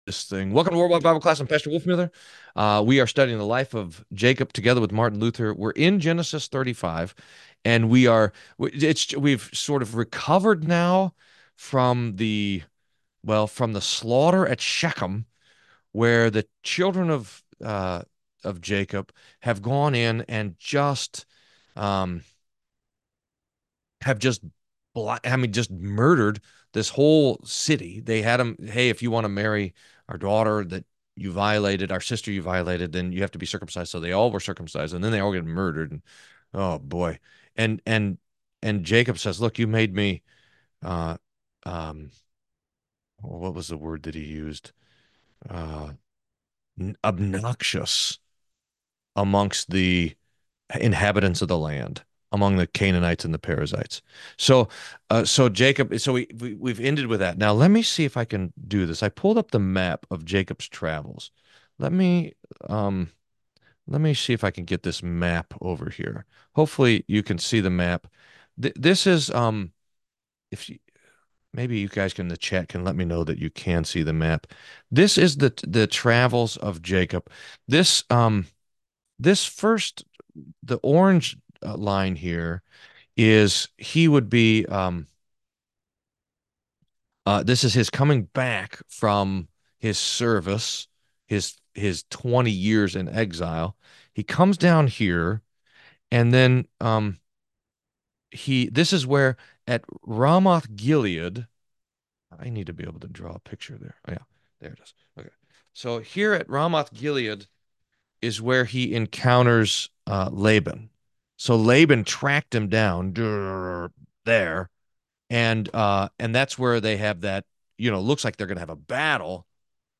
World-Wide Bible Class